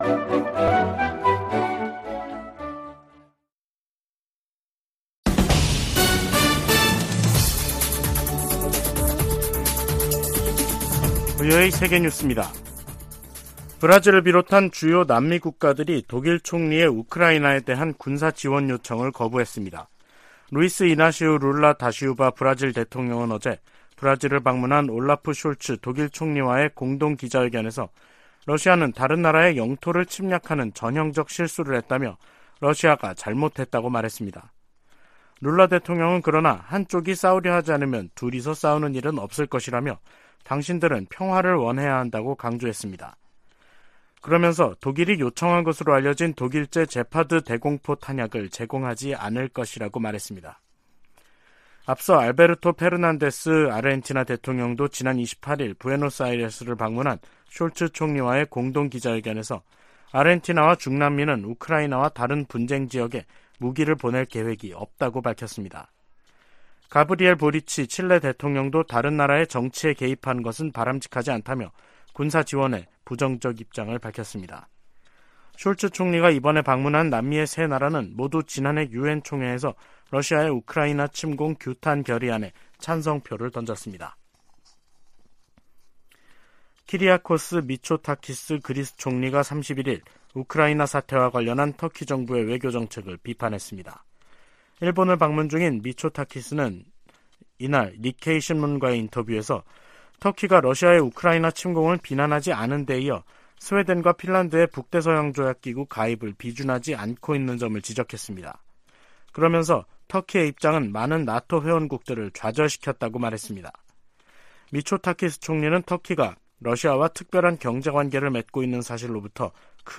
VOA 한국어 간판 뉴스 프로그램 '뉴스 투데이', 2023년 1월 31일 3부 방송입니다. 미-한 두 나라 국방 장관들이 서울에서 회담을 갖고, 한국에 대한 미국의 확고한 방위공약을 보장하기 위해 미국의 확장억제 실행력 강화 조치들을 공동으로 재확인해 나가기로 했습니다. 북한이 함경남도 마군포 엔진시험장에서 고체연료 엔진 시험을 한 정황이 포착됐습니다.